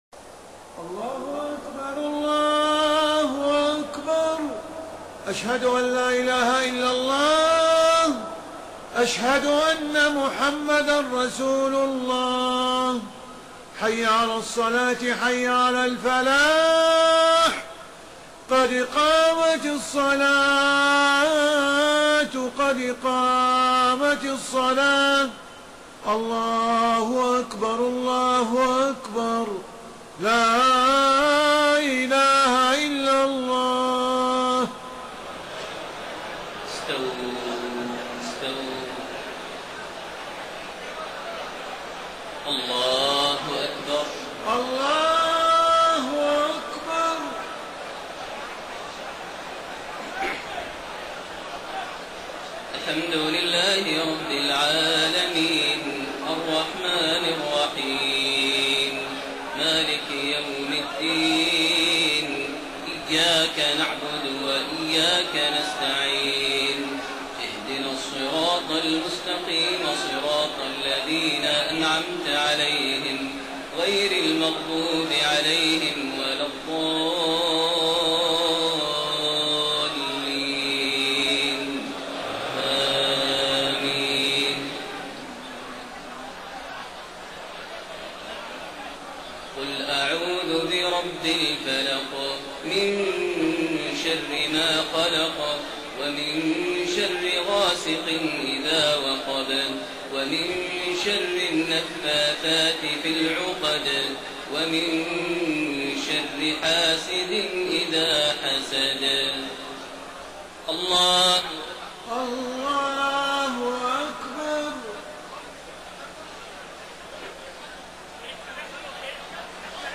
صلاة العشاء 12 ذو الحجة 1432هـ سورتي الفلق و الناس > 1432 هـ > الفروض - تلاوات ماهر المعيقلي